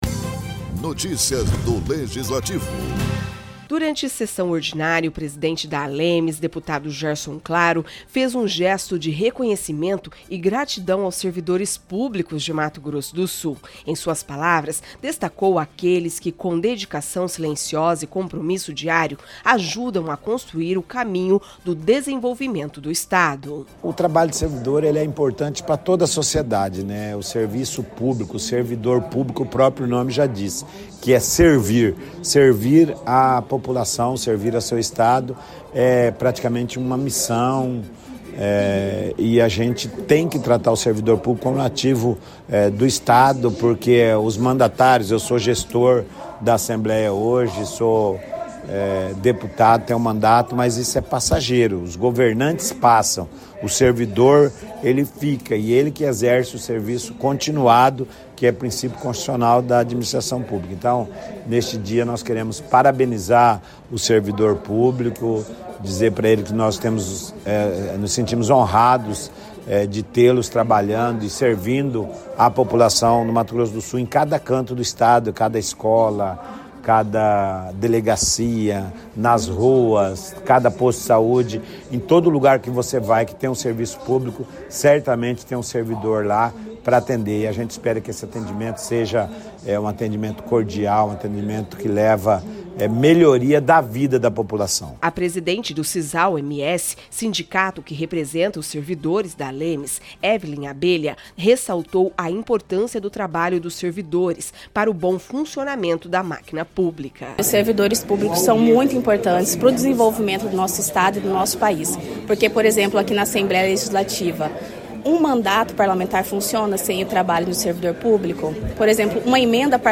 Durante a sessão ordinária desta terça-feira, a Assembleia Legislativa de Mato Grosso do Sul  (ALEMS) prestou homenagem aos servidores públicos pelo Dia do Servidor.